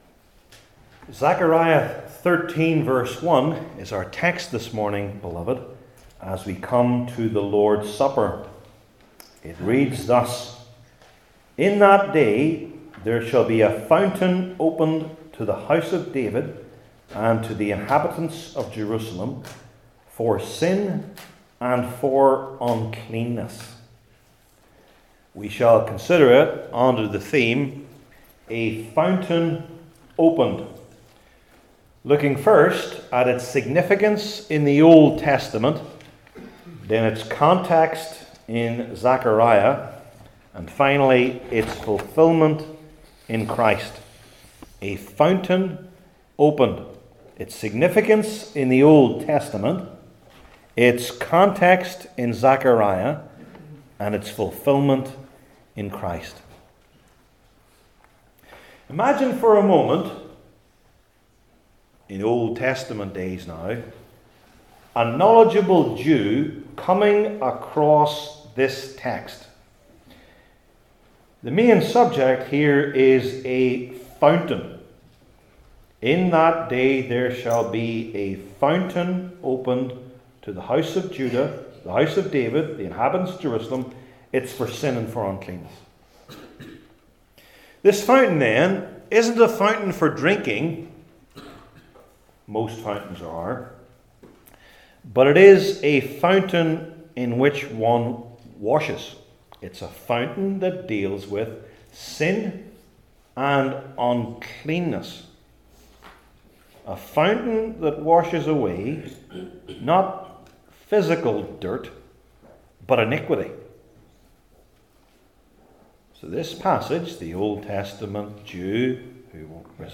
Old Testament Individual Sermons I. Its Significance in the Old Testament II.